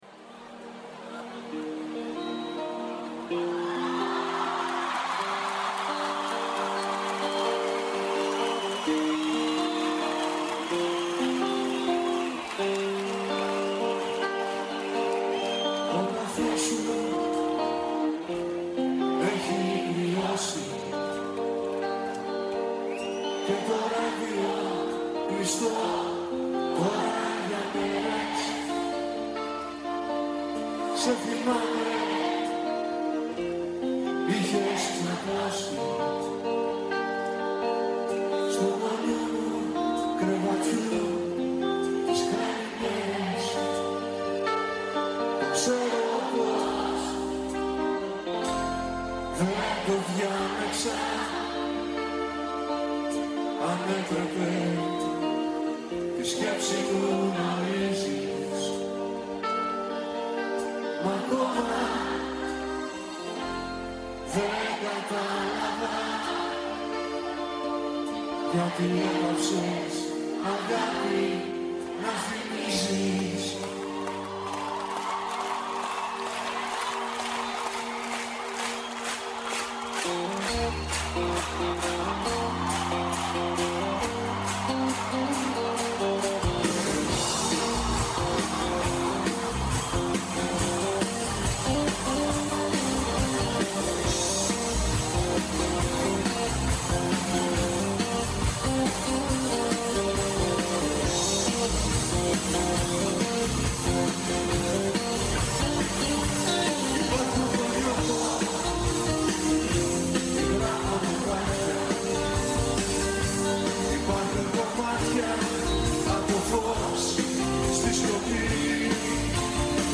Ενστάσεις, αναλύσεις, παρατηρήσεις, αποκαλύψεις, ευχές και κατάρες, τα πάντα γίνονται δεκτά. Όλα όσα έχουμε να σας πούμε στο Πρώτο Πρόγραμμα της Ελληνικής Ραδιοφωνίας, Δευτέρα έως και Πέμπτη, 1 με 2 το μεσημέρι.